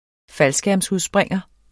Udtale [ ˈfalsgæɐ̯ms- ]